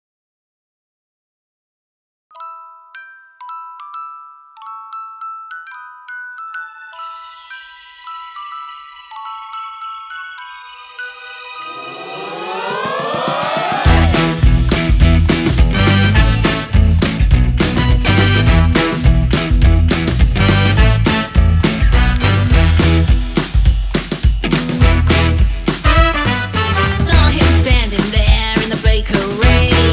Genre: Comedy